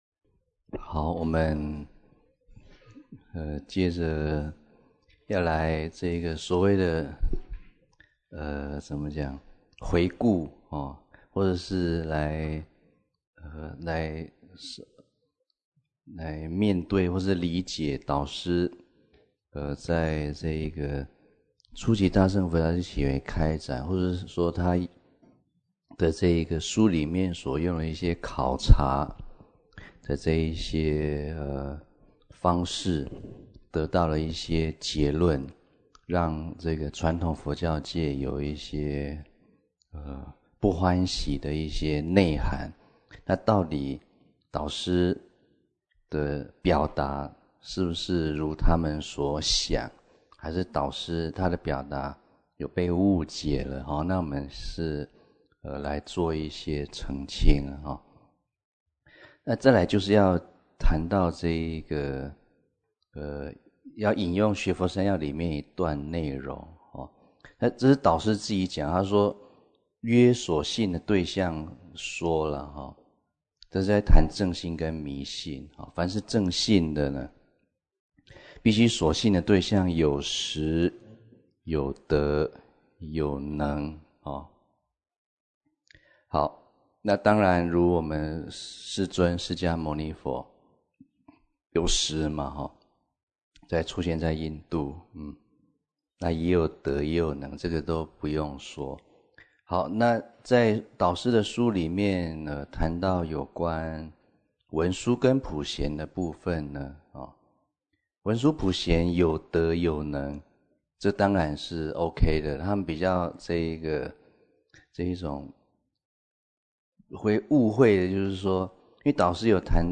地點：福嚴推廣教育班